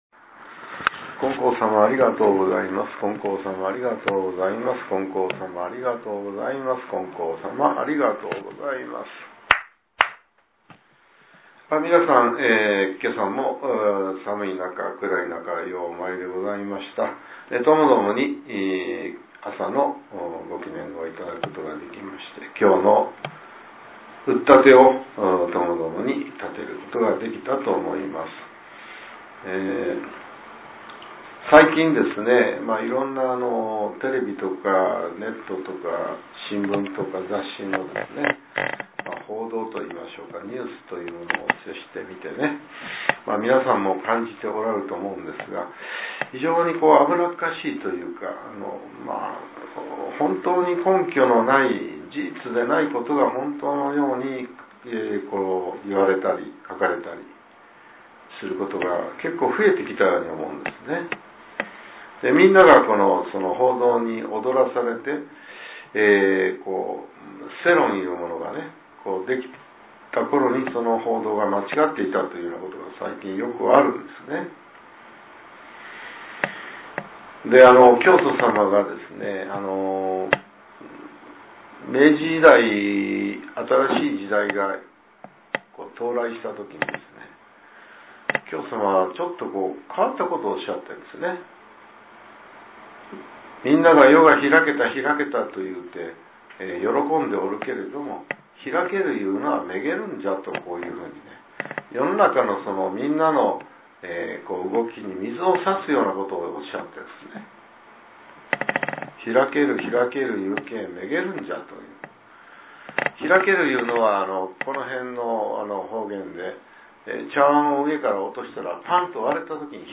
令和７年２月１５日（朝）のお話が、音声ブログとして更新されています。